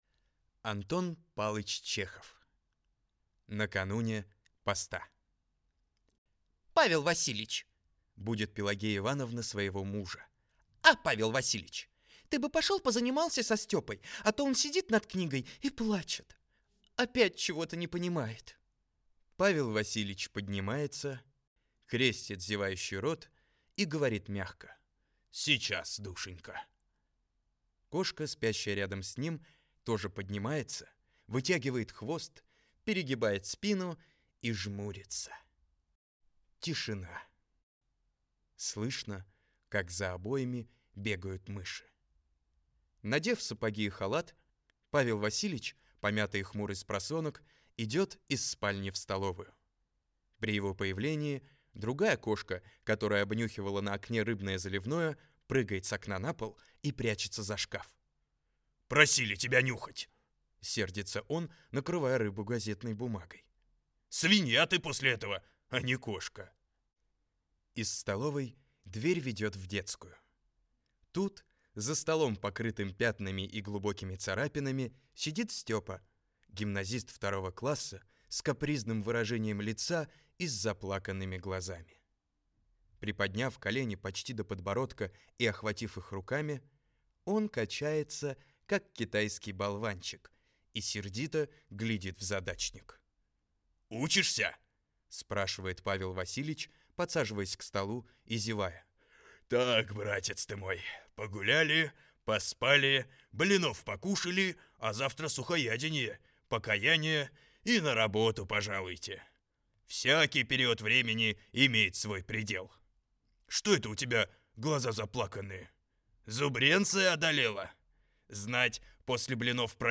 Aудиокнига Накануне поста